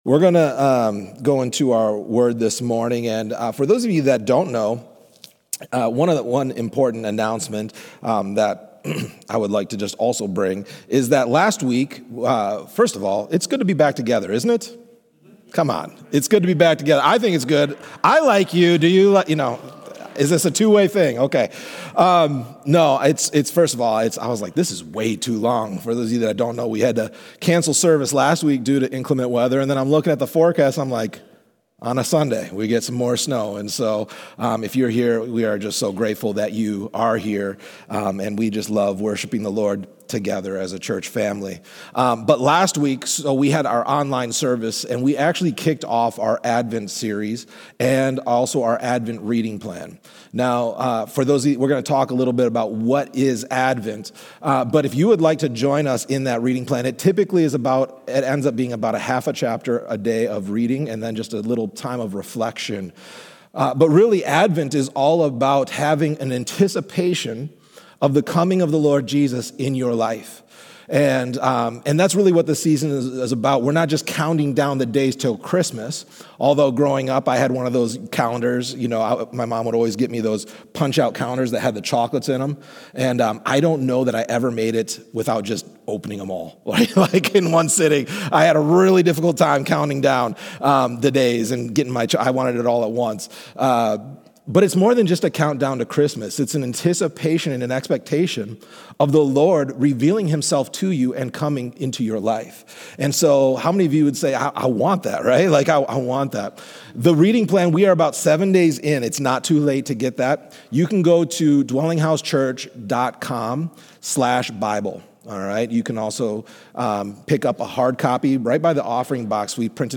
This Sermon Answers: 1.